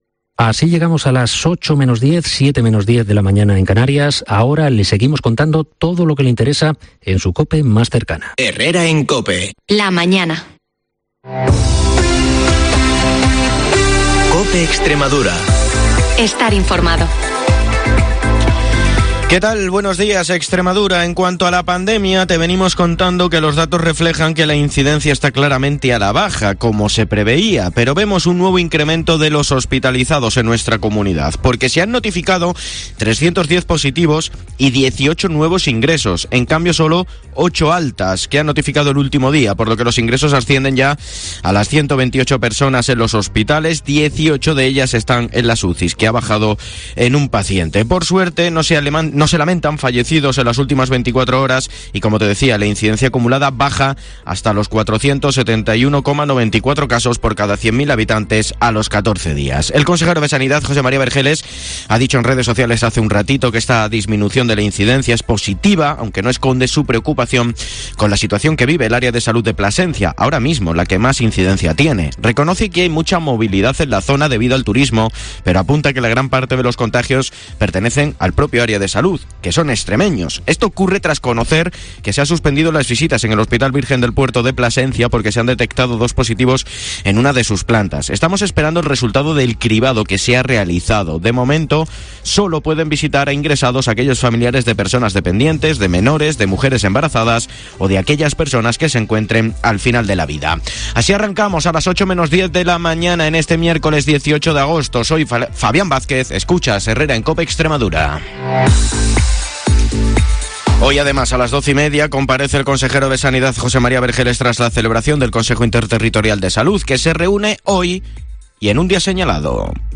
De lunes a viernes a las 7:20 y las 7:50 horas el informativo líder de la radio en la región